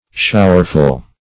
Showerful \Show"er*ful\, a.